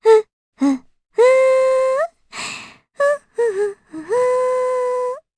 Artemia-Vox_Hum_jp.wav